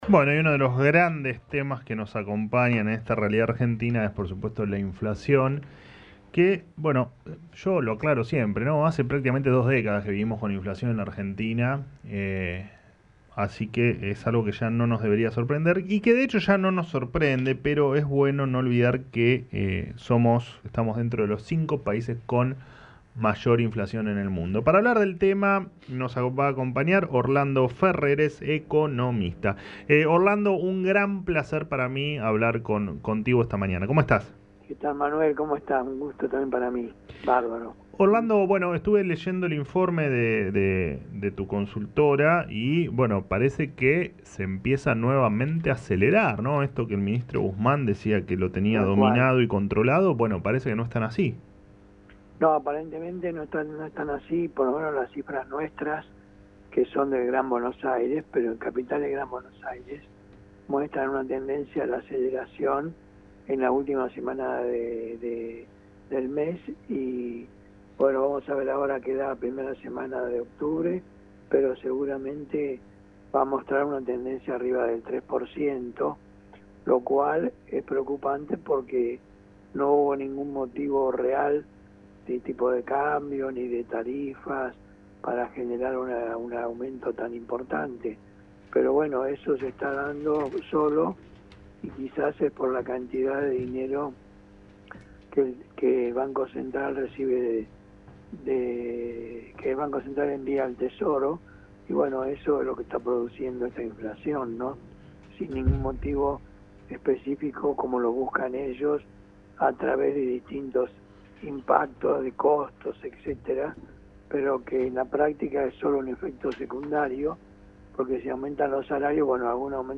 El economista Orlando Ferreres dialogó en Alguien Tiene Que Decirlo sobre el índice de inflación del último mes y expresó su preocupación “porque no hubo ningún motivo real para generar un aumento tan importante”.